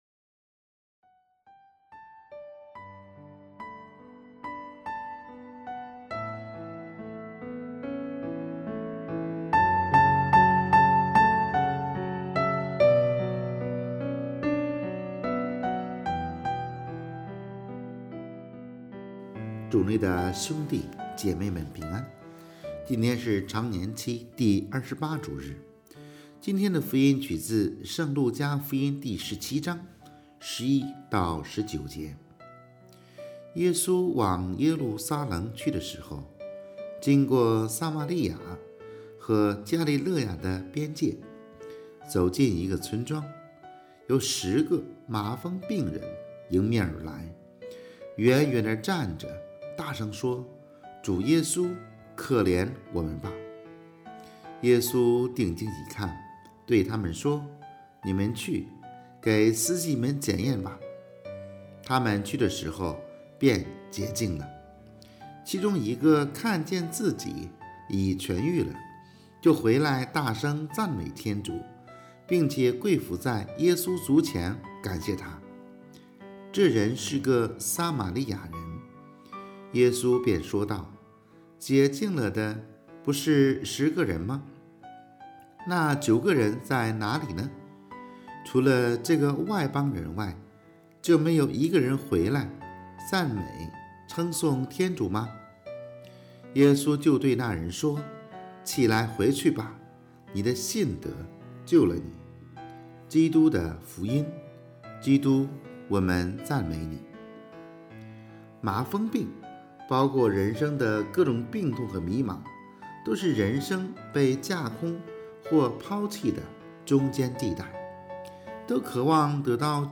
【主日证道】|麻风病人的不幸与有幸（丙-常28主日）